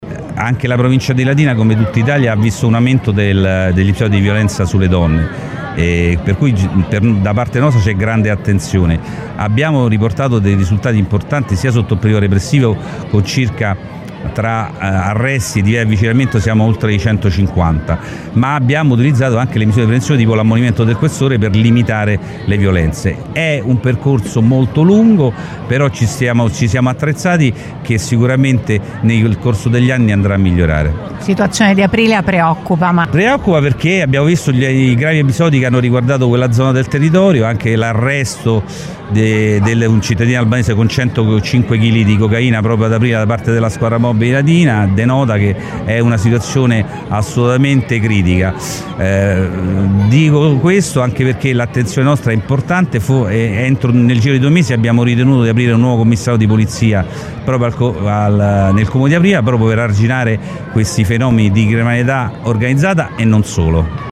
TERRACINA – Diminuiscono i reati nel loro complesso, ma aumentano le violenze sulle donne; preoccupano i reati giovanili e la situazione di Aprilia. Sono alcuni dei dati sui emersi dalla relazione del Questore di Latina, Fausto Vinci durante la cerimonia che si è tenuta questa mattina al teatro romano di Terracina in occasione delle celebrazioni per il 173° della Fondazione della Polizia di Stato.